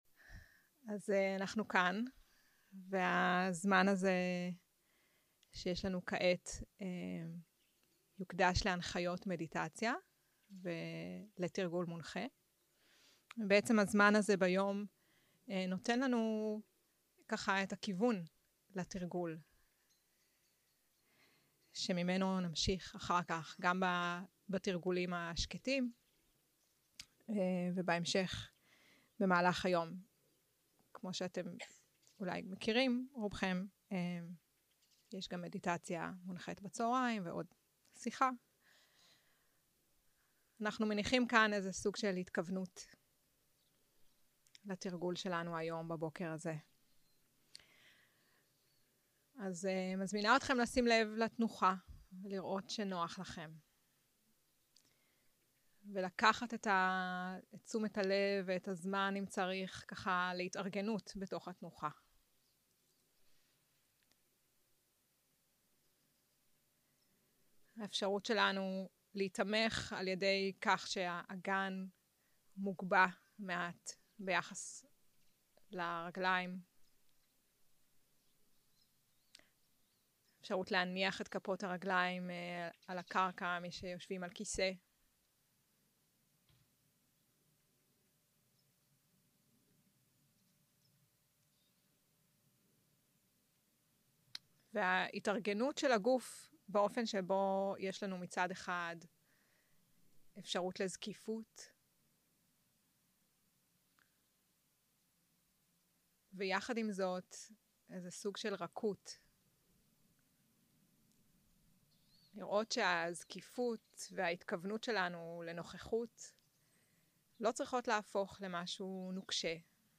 יום 2 - הקלטה 1 - בוקר - הנחיות למדיטציה - מוכנות ונוכחות במפגש עם מה שישנו 0:00 0:00 סוג ההקלטה: סוג ההקלטה: שיחת הנחיות למדיטציה שפת ההקלטה: שפת ההקלטה: עברית